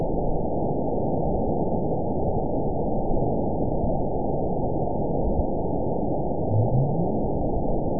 event 913851 date 04/22/22 time 14:08:54 GMT (3 years, 1 month ago) score 9.69 location TSS-AB01 detected by nrw target species NRW annotations +NRW Spectrogram: Frequency (kHz) vs. Time (s) audio not available .wav